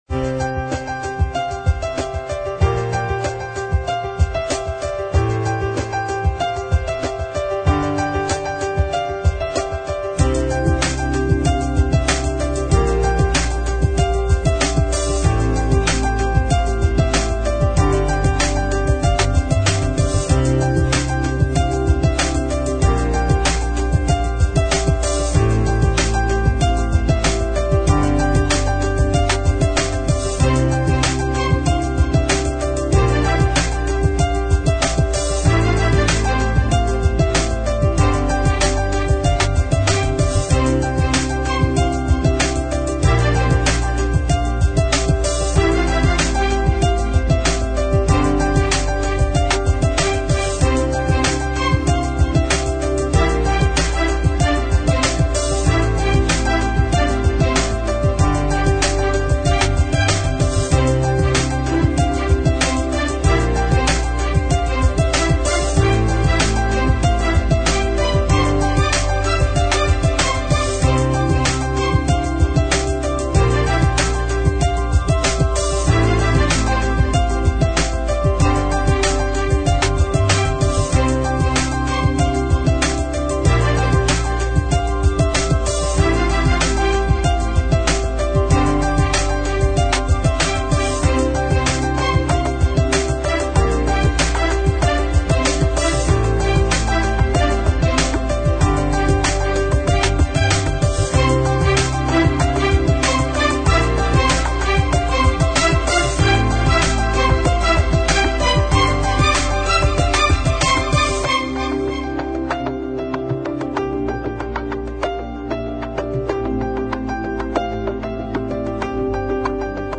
描述：具有振奋人心和鼓舞人心的感觉和生命力。
动人的鼓点和弦乐伴随着旋律优美的钢琴和圆润的垫音。
Sample Rate 采样率16-Bit Stereo 16位立体声, 44.1 kHz